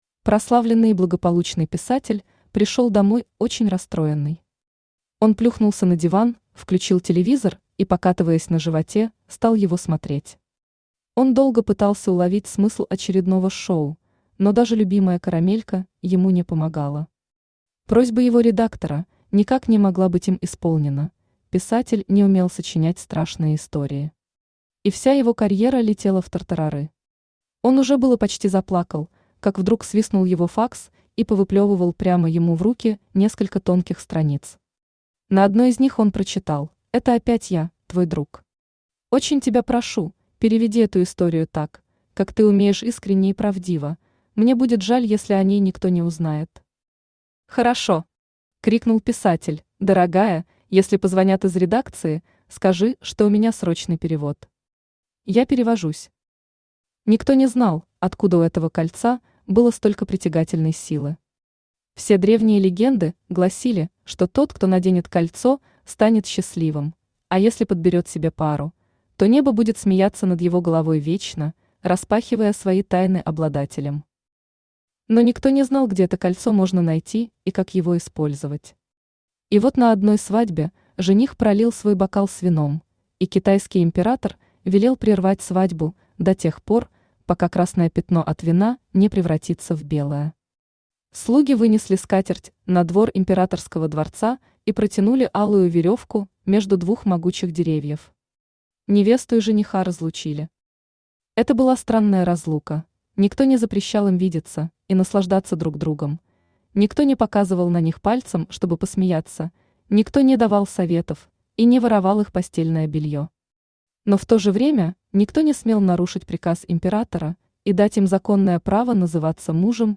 Аудиокнига Сказка о кольце | Библиотека аудиокниг
Aудиокнига Сказка о кольце Автор Оксана Лисковая Читает аудиокнигу Авточтец ЛитРес.